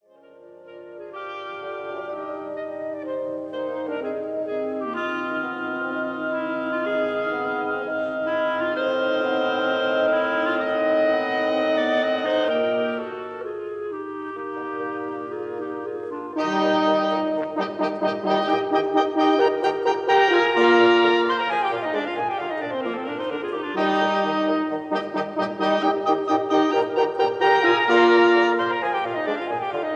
oboes
clarinets
bassons
horns